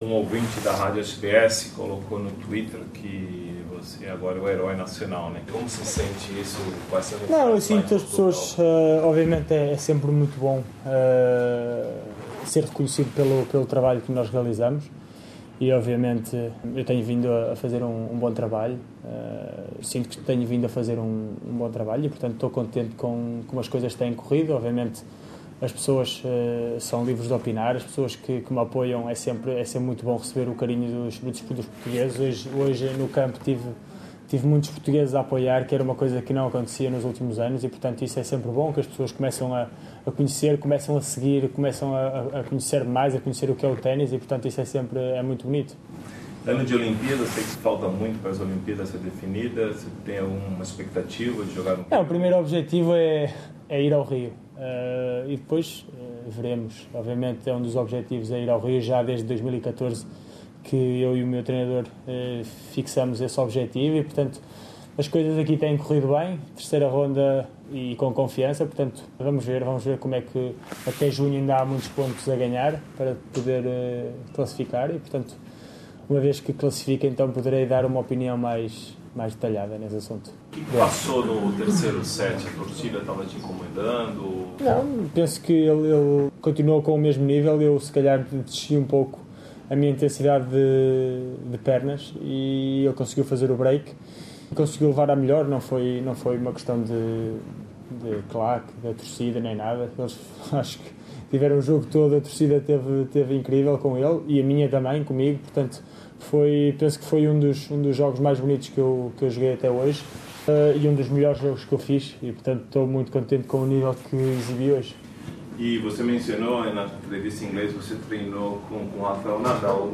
O número 1 do tênis em Portugal vai à terceira ronda do Aberto da Austrália, neste sábado, às 7 da noite, na Margaret Court Arena, enfrentando o número 2 do mundo. Em entrevista ao Programa Português da Rádio SBS, ele diz estar muito confiante, que o jogo com Santiago Giraldi foi um dos melhores da sua vida e que ficou muito feliz de ver que havia muitos portugueses acompanhando o jogo no estádio.